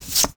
draw.wav